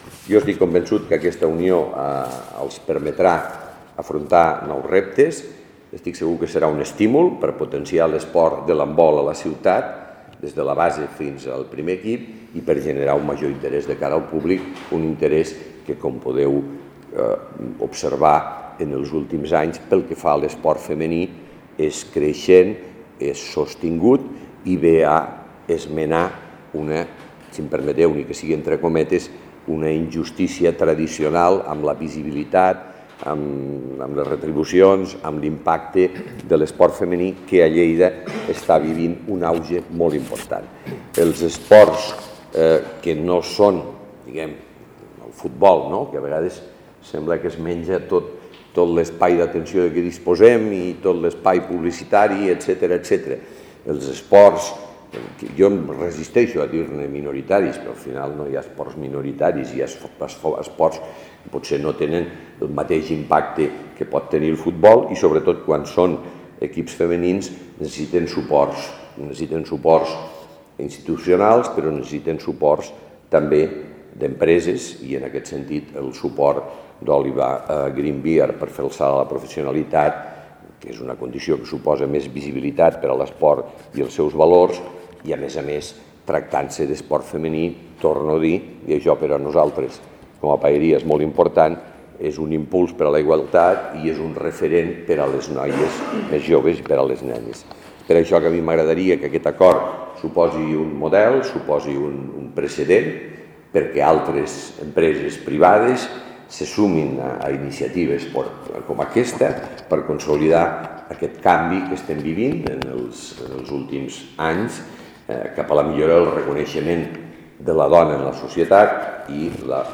La Paeria ha estat avui l'escenari de la presentació d’aquest nou projecte, presidida pel Paer en Cap, Miquel Pueyo, qui ha animat a altres empreses a que se sumin a donar suport a iniciatives d’impuls a l’esport femení a la ciutatLleida acollirà la fase d’ascens a la divisió d’honor plata femenina on hi participarà l’equip els dies 13, 14 i 15 de maig al pavelló municipal Onze de Setembre
tall-de-veu-del-paer-en-cap-miquel-pueyo